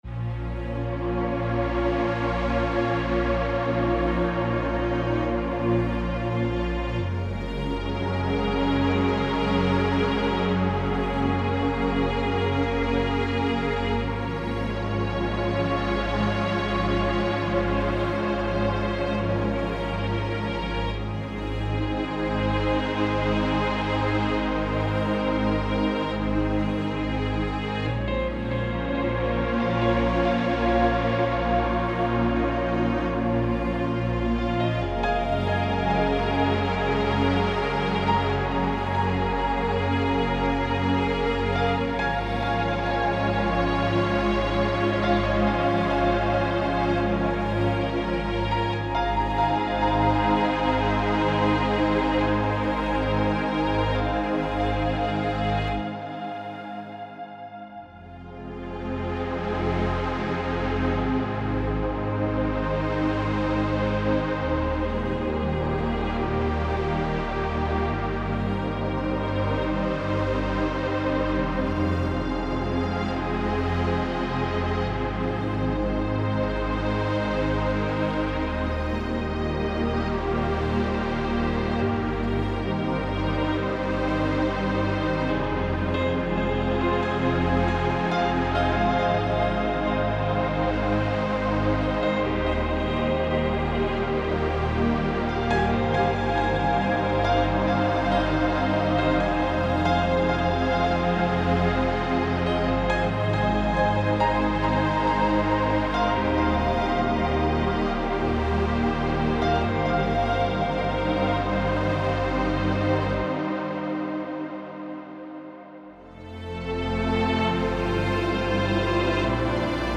Type: Midi Samples
Piano, String, Pad for any Kit Plus Demo